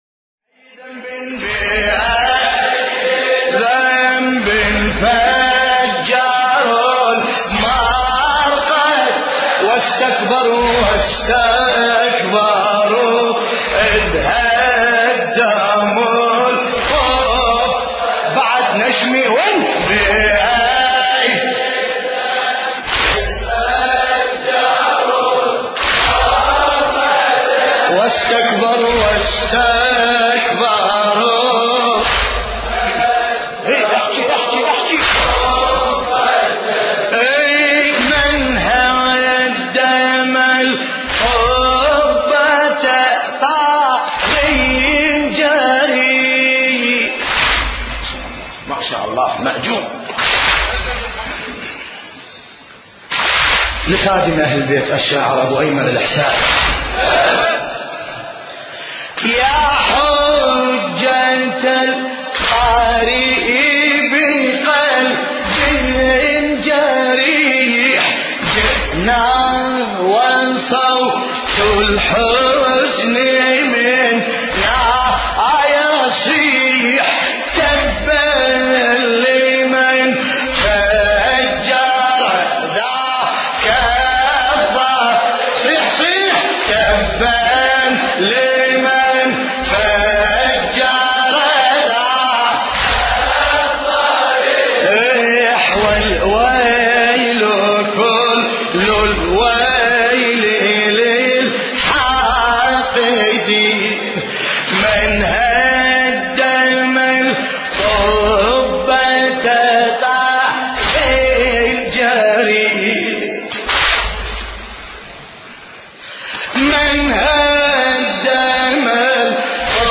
:Sham: ملا باسم کربلایی :Sham:
مرثیه خوانی به زبان عربی